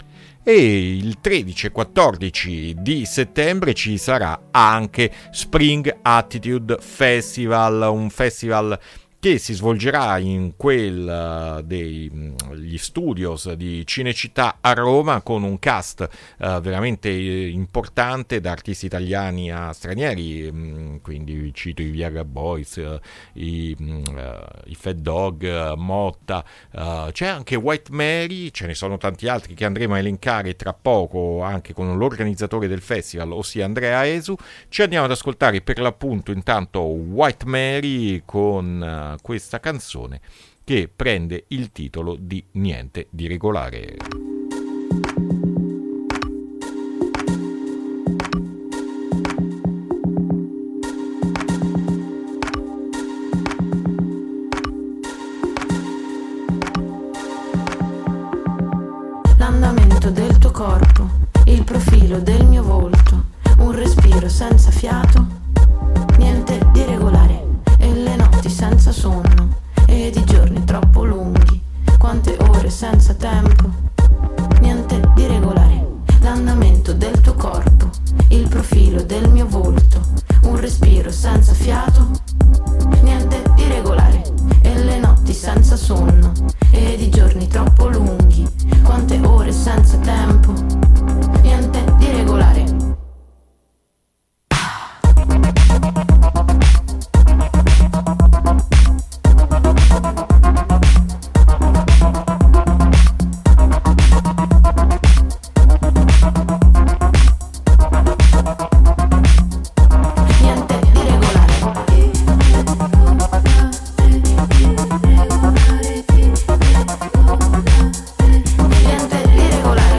intervista-springattitude2024.mp3